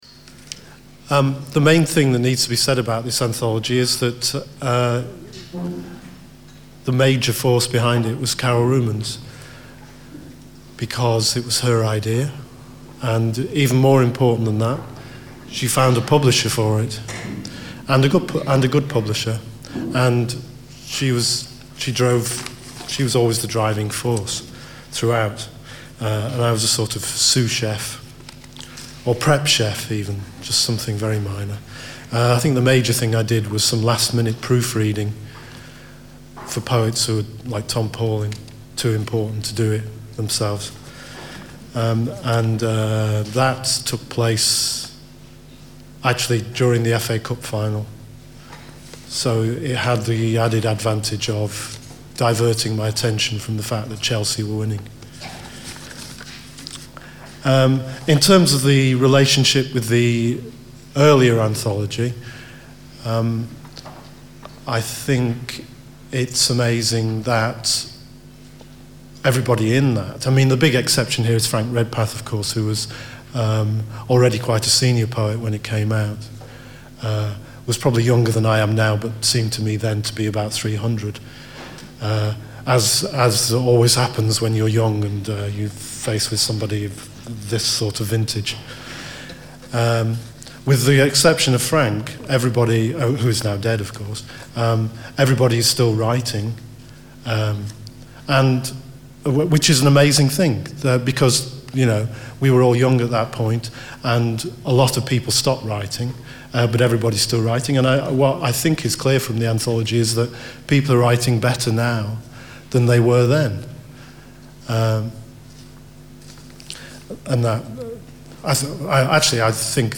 Old City : New Rumours - Book launch event
We celebrate continuity as well as new beginnings in the wonderful variety of poetry represented in Old City: New Rumours. As many as possible of the featured poets will be invited to give short readings this event.